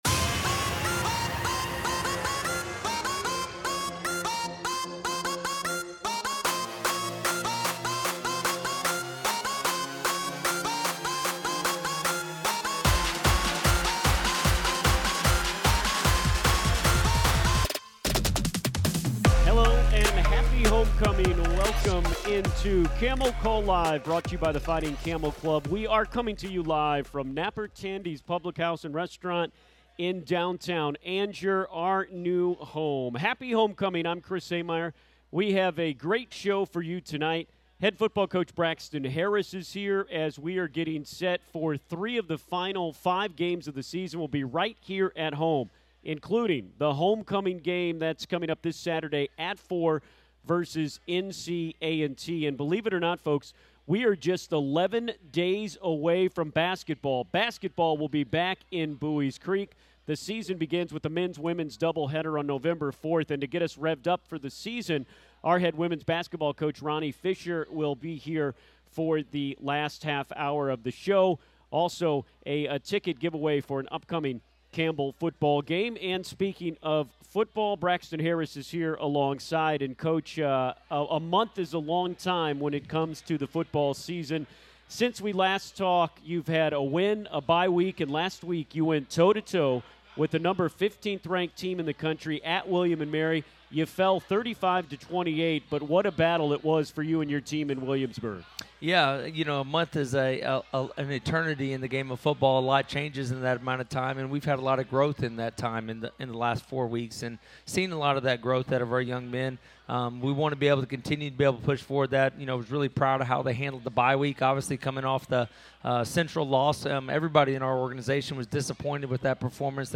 It's Camel Call Live from Napper Tandy's in Angier.
CC_live_from_downtown_oct_24.mp3